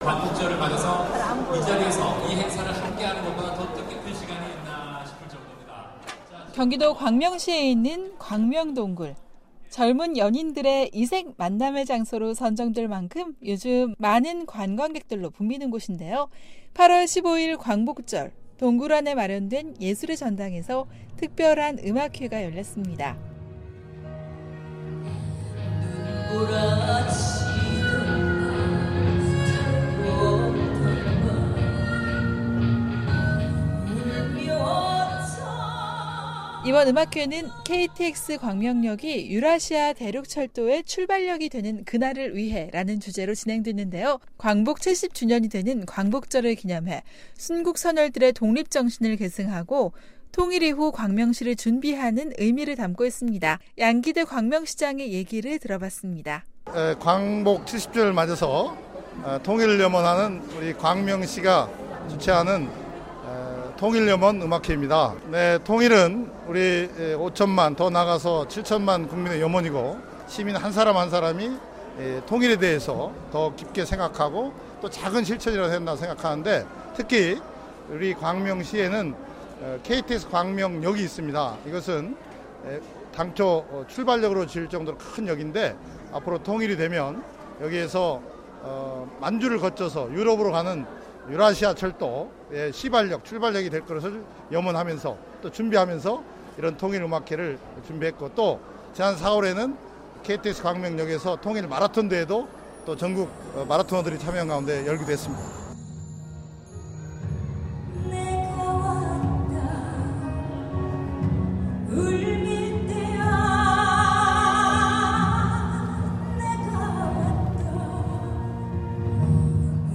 광복절인 15일 경기도 광명시 광명동굴에서 광복 70주년을 기념하고 통일을 염원하는 ‘광명 통일 염원 음악회’가 열렸습니다.